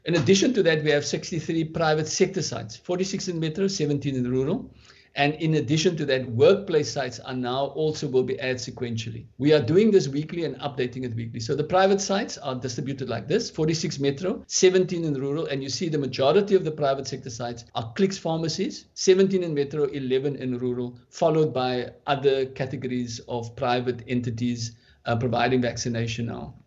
Head of Health, Dr. Keith Cloete says the additional clinic would significantly aid in ramping up the province’s vaccination capabilities.